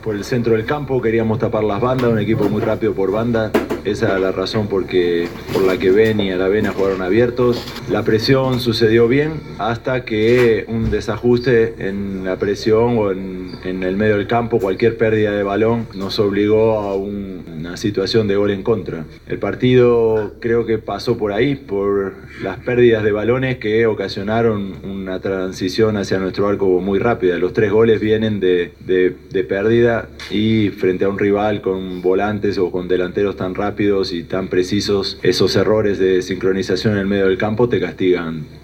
En la conferencia de prensa tras el encuentro, el estratega reconoció que el vértigo de los punteros extremos de la Celeste, Darwin Núñez y Nicolás de la Cruz, complicaro al mediocampo y defensa nacional, pero, apuntó también a los errores propios, sindicando que «los tres goles vienen de pérdidas (de balón)».